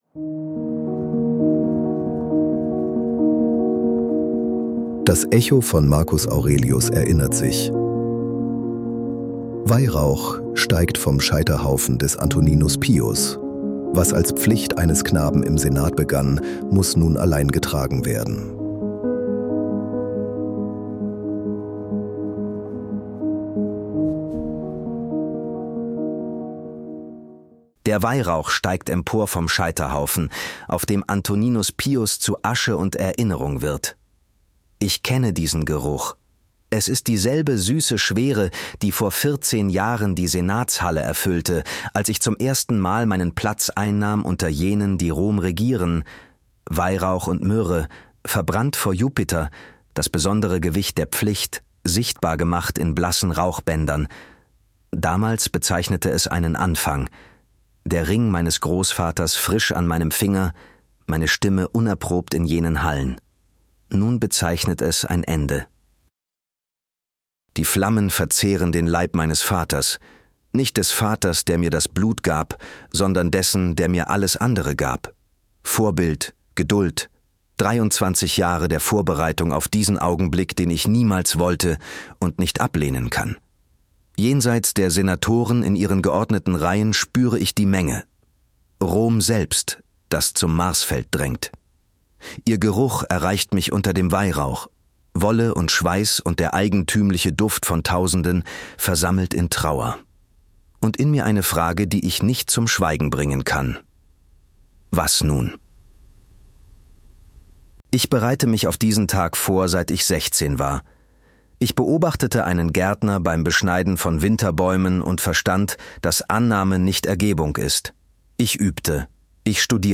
Ein stoisches Hörbuch in Ich-Form über Marcus Aurelius (Teil 8 von 12).
Wir nutzen synthetische Stimmen, damit diese Geschichten kostenlos bleiben, ohne Werbung — und dich in mehreren Sprachen erreichen.